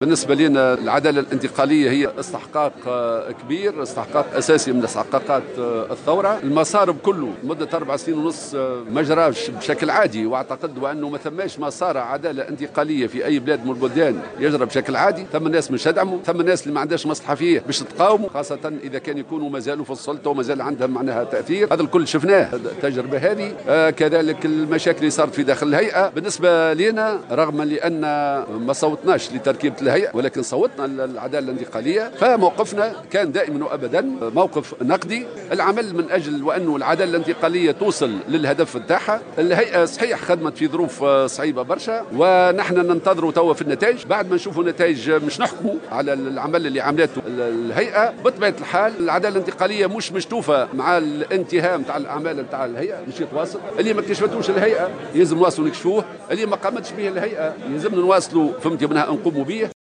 قال الناطق الرسمي باسم الجبهة الشعبية، حمة الهمامي في تصريح لمراسلة الجوهرة "اف ام" اليوم الجمعة إن مسار العدالة الإنتقالية لن ينتهي بانتهاء عمل هيئة الحقيقة والكرامة وما لم تكشفه الهيئة سيتم كشفه في قادم الأيام والأشهر على حد قوله.